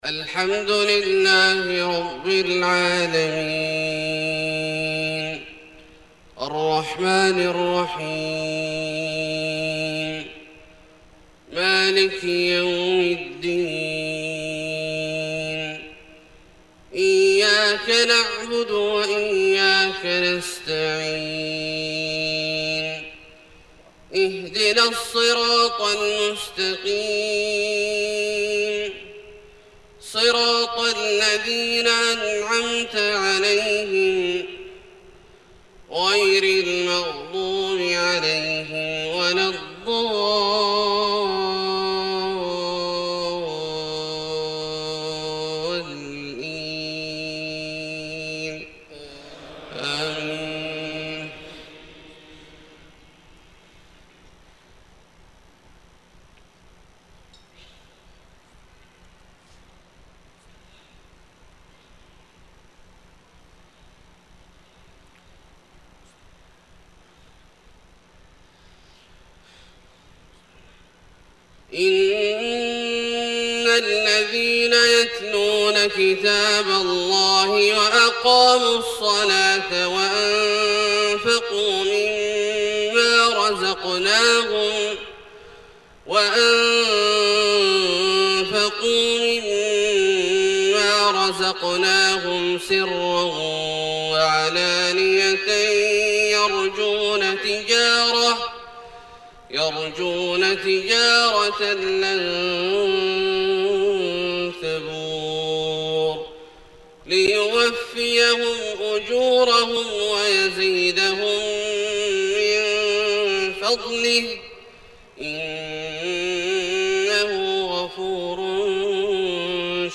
صلاة الفجر 2-7-1431 من سورة فاطر {29-45} > ١٤٣١ هـ > الفروض - تلاوات عبدالله الجهني